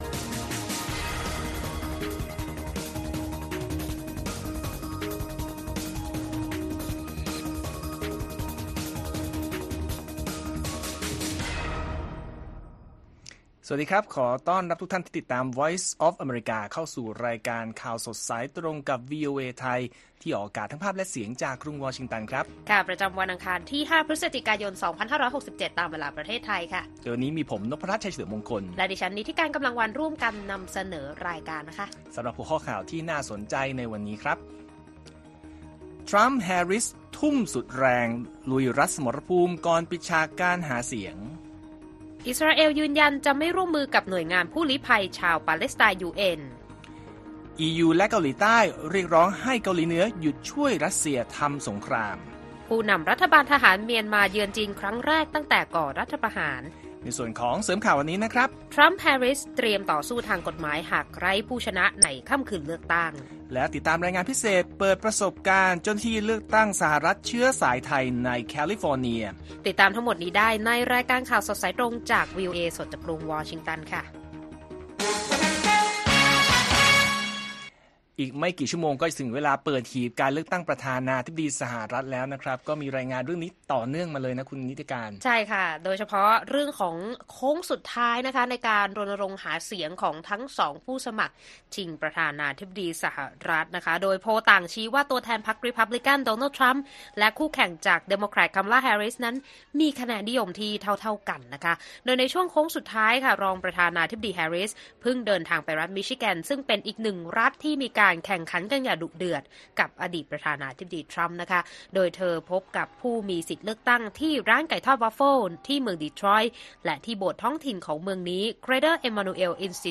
ข่าวสดสายตรงจากวีโอเอไทย อังคาร ที่ 5 พ.ย. 67